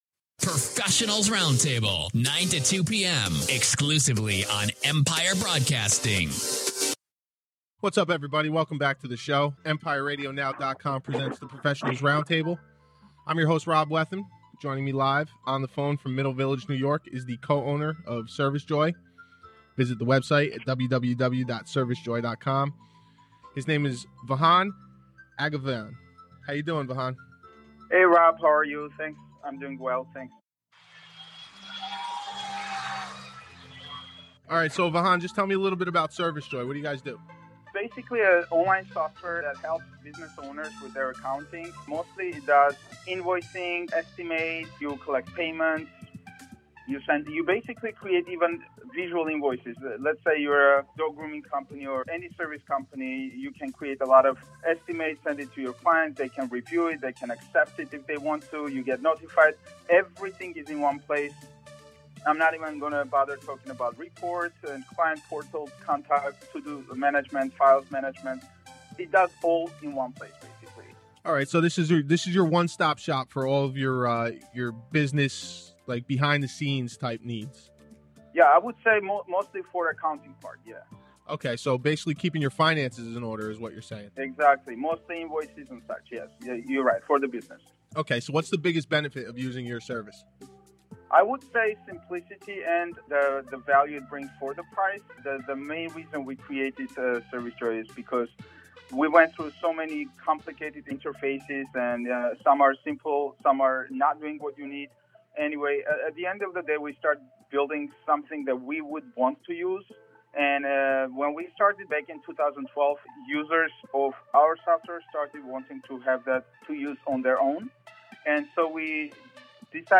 This interview was recorded in New York in April of 2017.